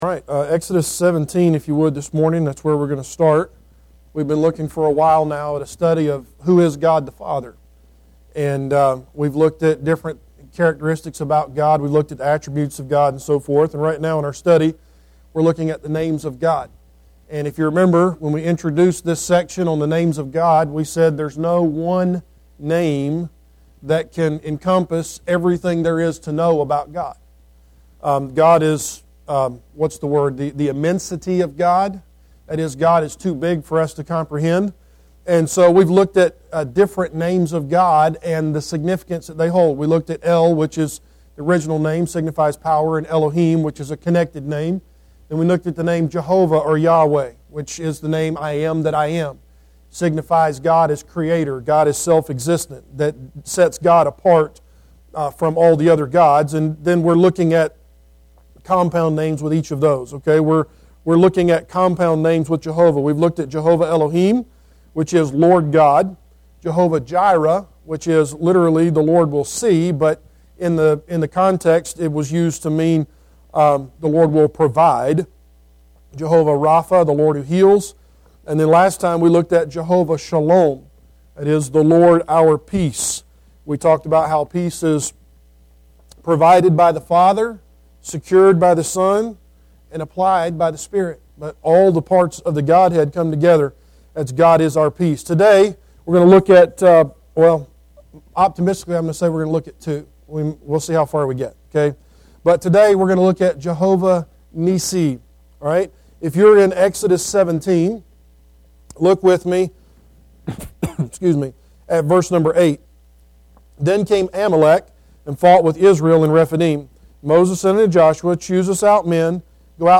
The Names of God Service Type: Adult Sunday School Class Preacher